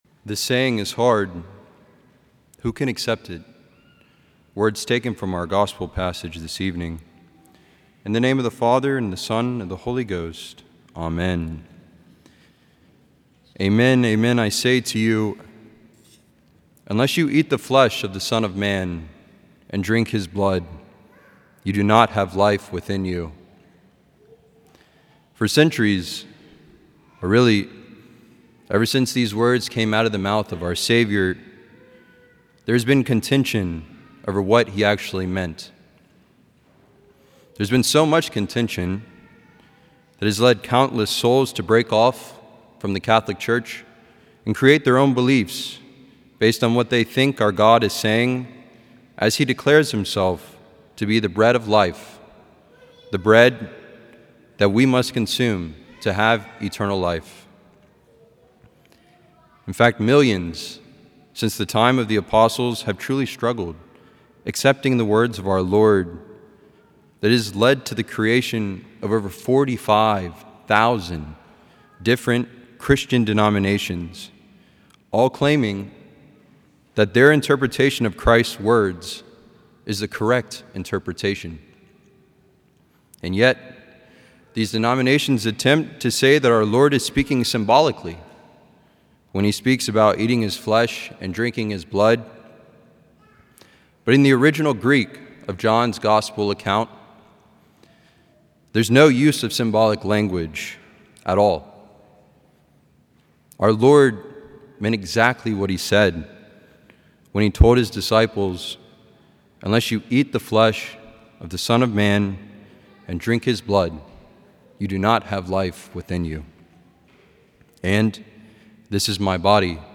Homily
A homily from the series "Homilies."